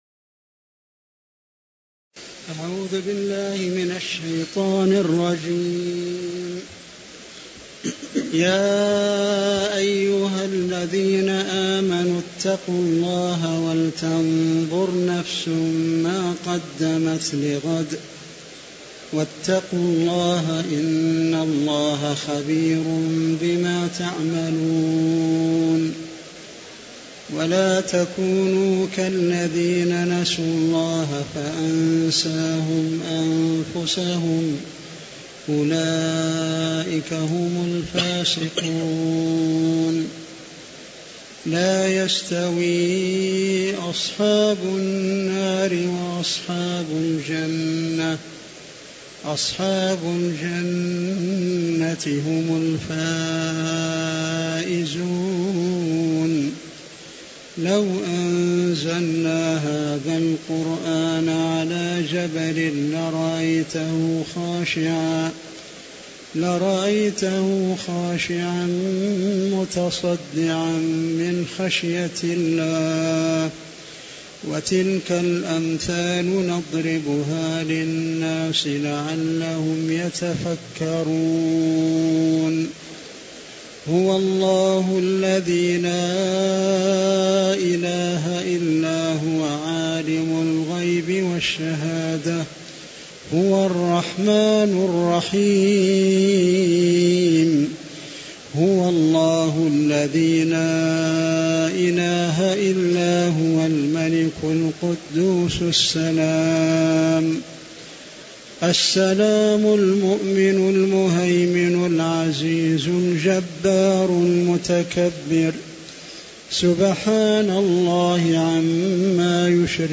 تاريخ النشر ١٣ شوال ١٤٤٦ هـ المكان: المسجد النبوي الشيخ: فضيلة الشيخ د. محمد بن محمد المختار فضيلة الشيخ د. محمد بن محمد المختار باب الفدية (07)